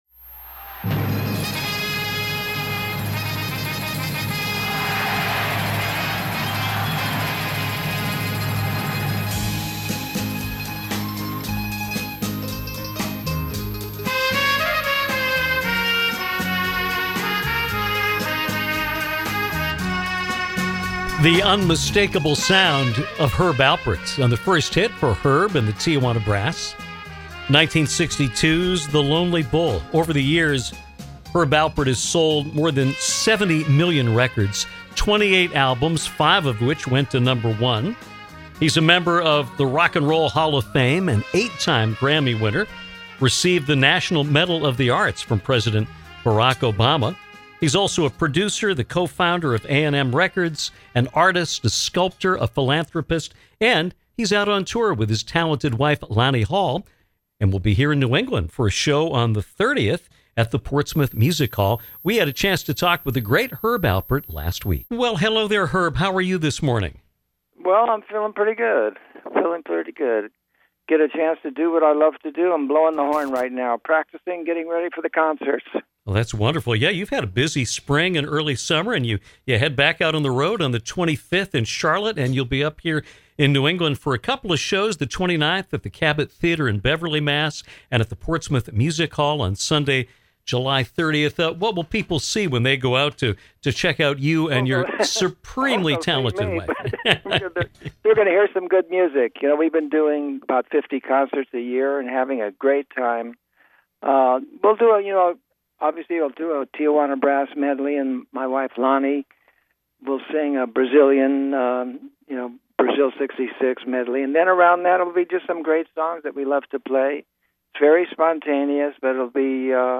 The legendary Herb Alpert joins us for a lengthy conversation about music, art, the Tijuana Brass, Sam Cooke, A & M Records, and more, as he prepares to hit the […]